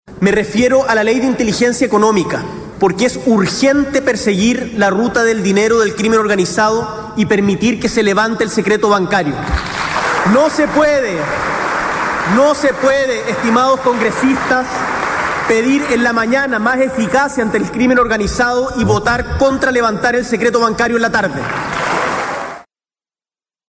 115-cuna-tl-discurso-boric-secreto-bancario.mp3